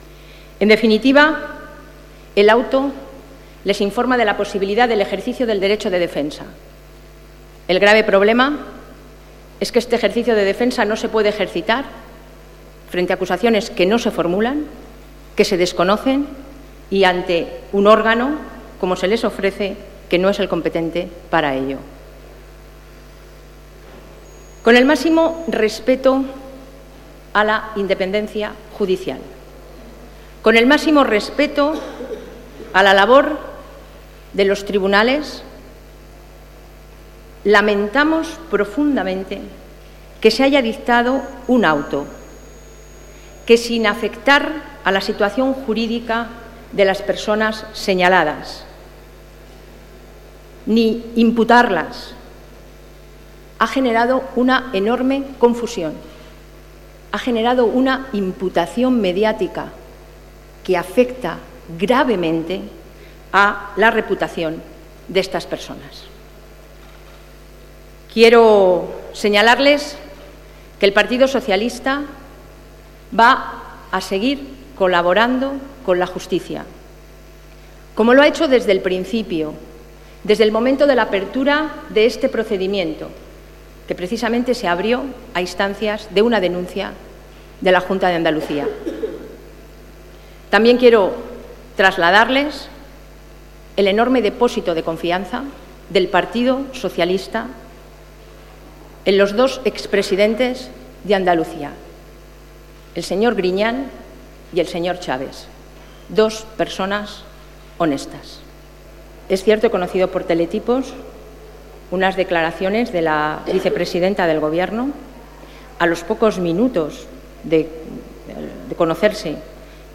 2ª parte de las declaraciones de Soraya Rodríguez sobre el último auto de la juez Alaya